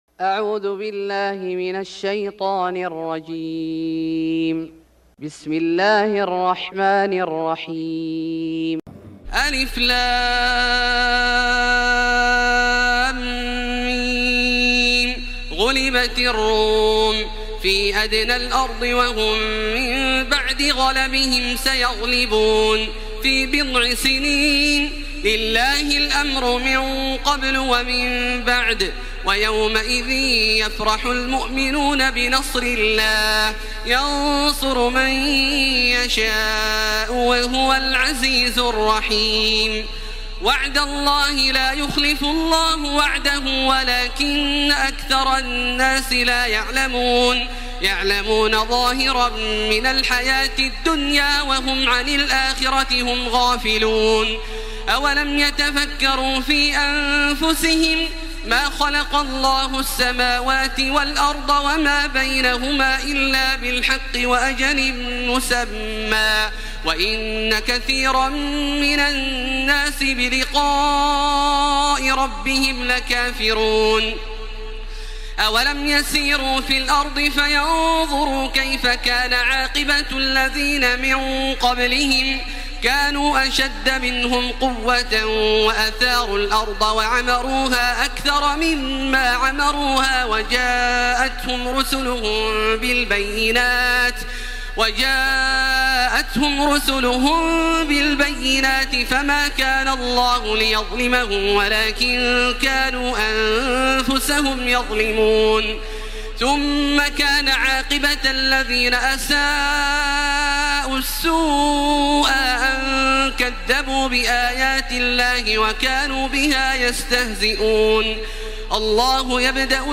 سورة الروم Surat Ar-Rum > مصحف الشيخ عبدالله الجهني من الحرم المكي > المصحف - تلاوات الحرمين